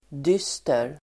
Uttal: [d'ys:ter]